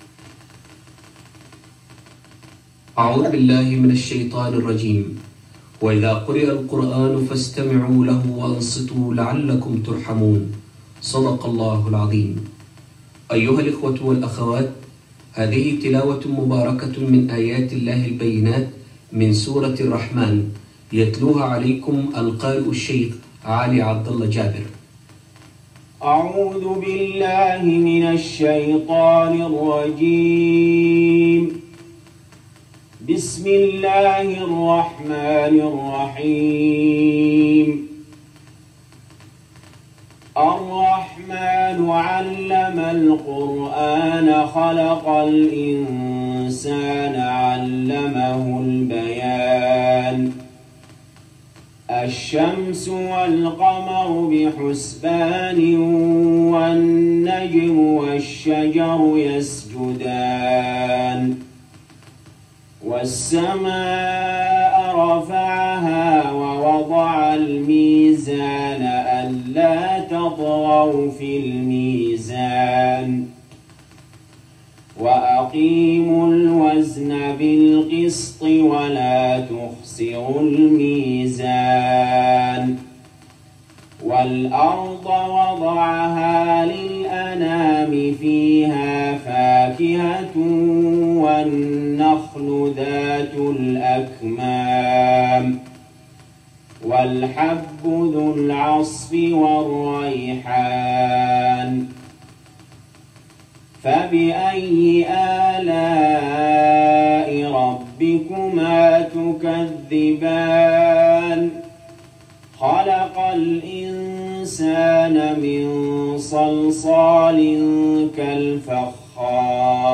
| نادرة مميزة تعرض لأول مره | ماتيسر من سورة الرحمن 1-45 | > تلاوات الشيخ علي جابر في التلفزيون السعودي عام 1408هـ > تلاوات الشيخ علي جابر خارج الحرم > المزيد - تلاوات الحرمين